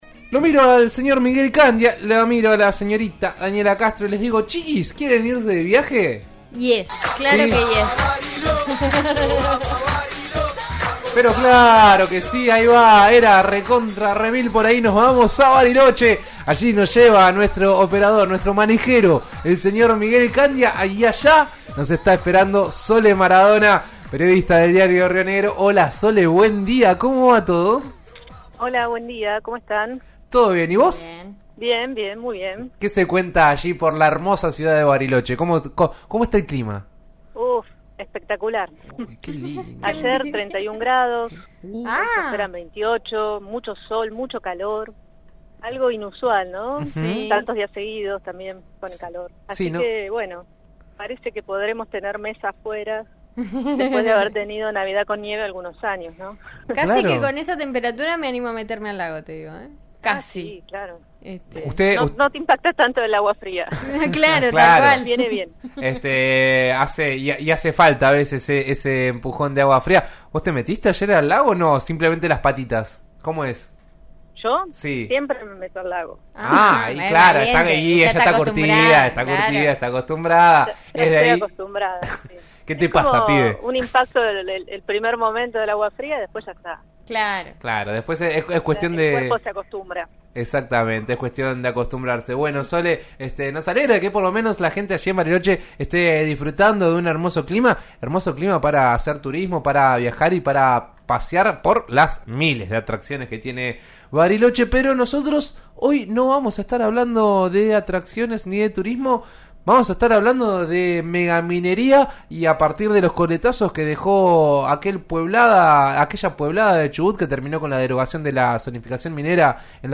dijo presente en En eso estamos de RN Radio (89.3) con su columna de los jueves para hablar sobre el tema.